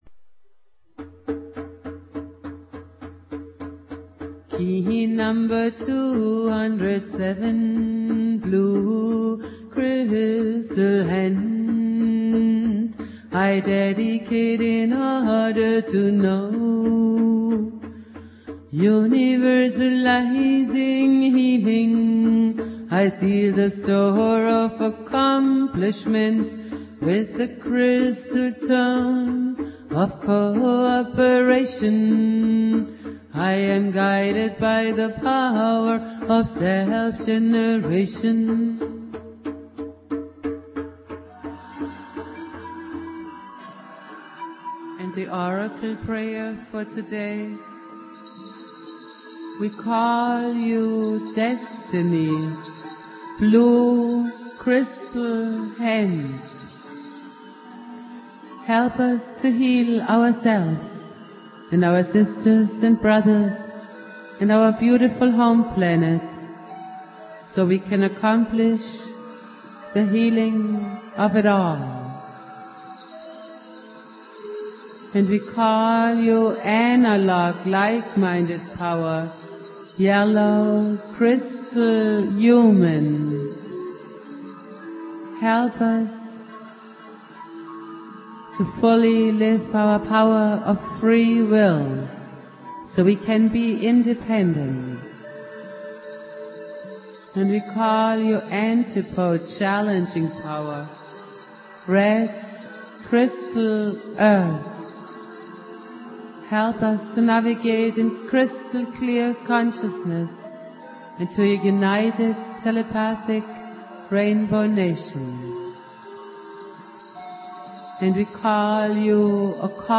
Prayer
Jose Argüelles playing flute
produced at High Flowing Recording Studio